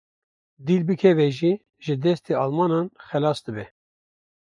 Pronounced as (IPA)
/xɛˈlɑːs/